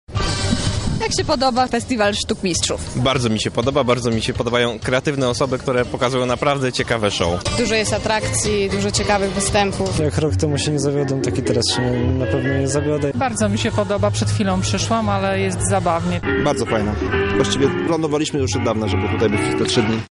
Zakończył się Carnaval Sztukmistrzów.